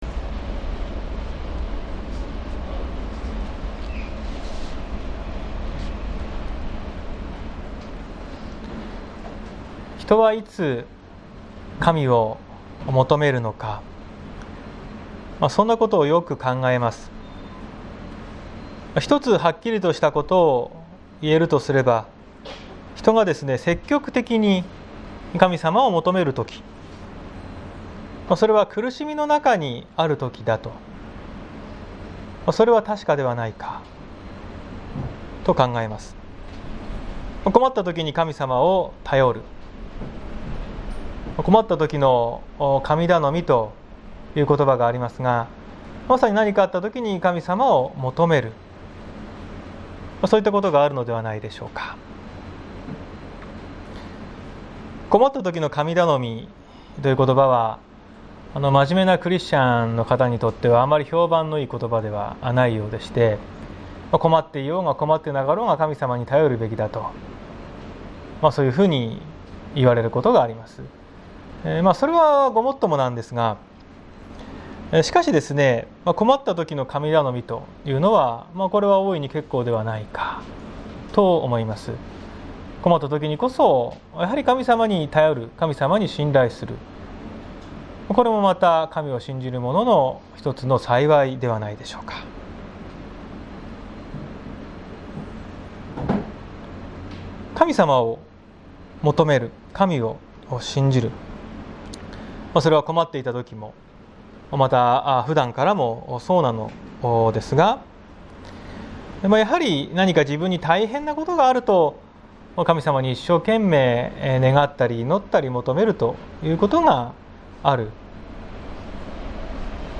2021年07月11日朝の礼拝「私を憐れんでください」綱島教会
説教アーカイブ。